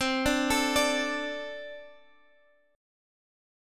D5/C chord